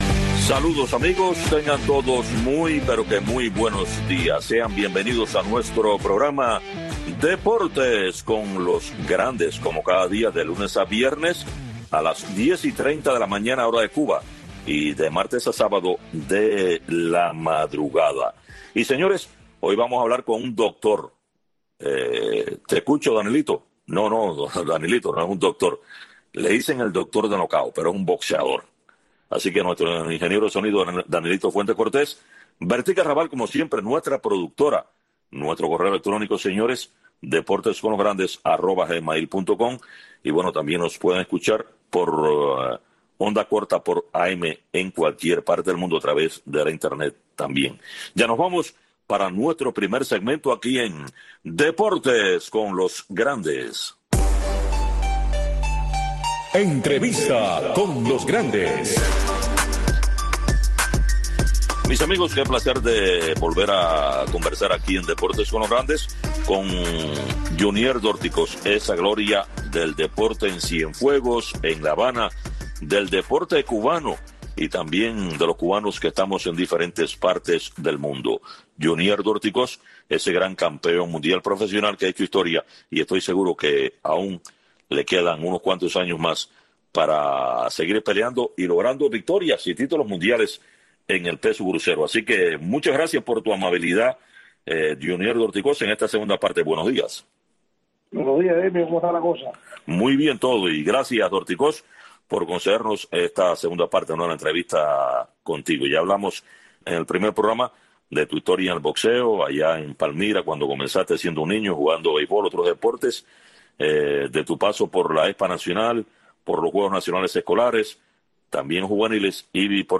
Entrevistas, comentarios y análisis de los grandes acontecimientos del deporte.